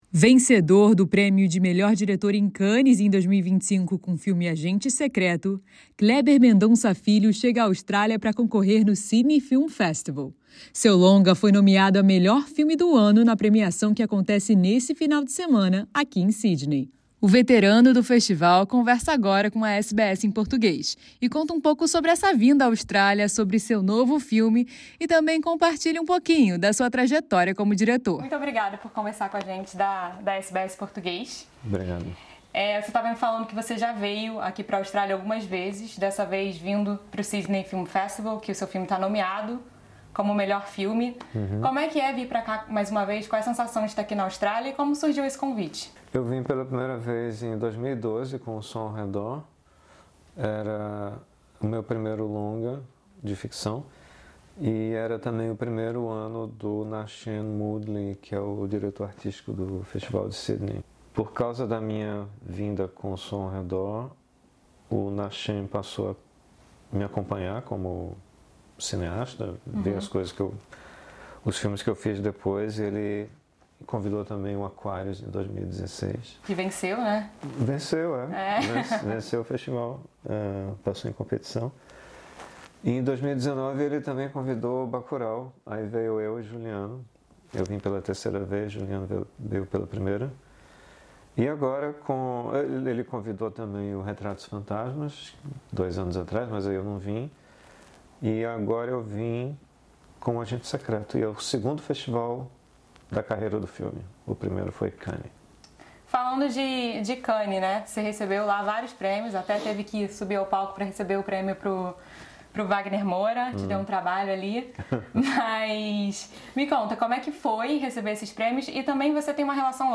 Confira AQUI a entrevista em formato de video.